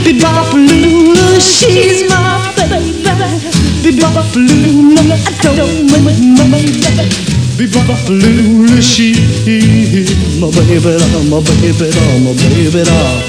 ultrasons.wav